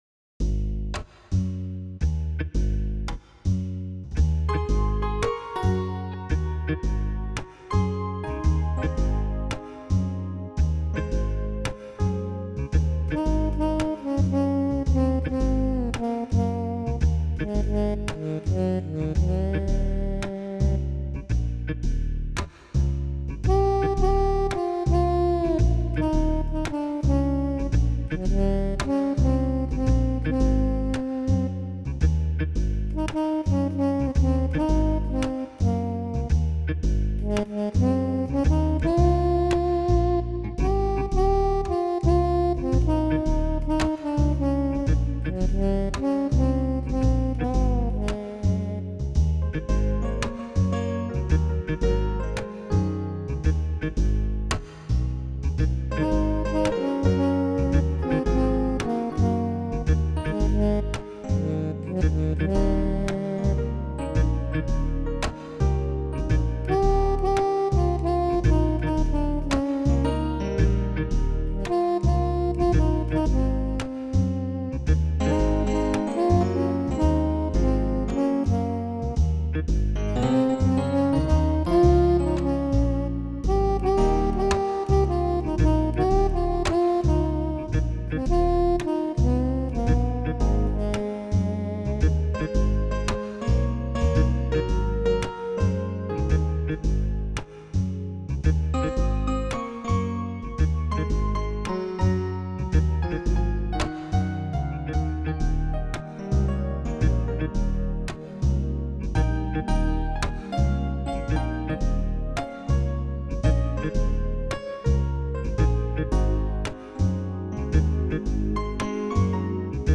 th2XV6WXQV  Download with Lead